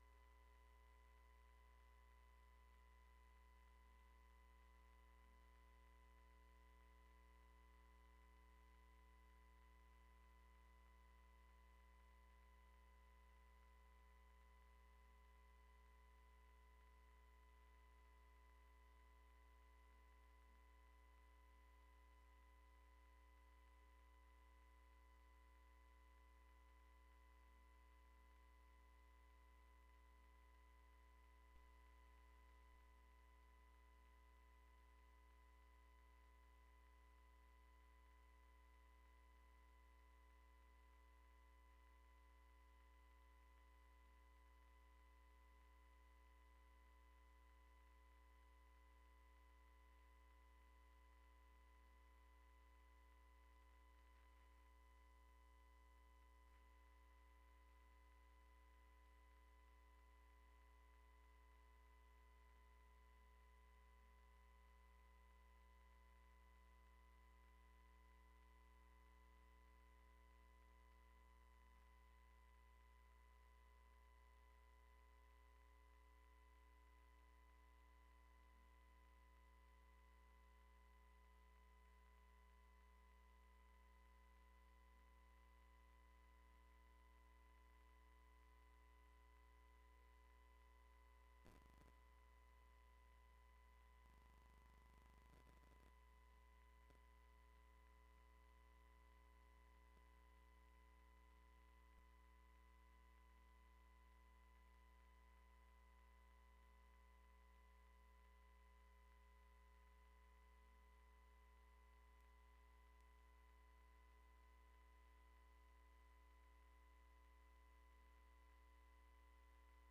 **Technical Difficulties until 2 minutes in**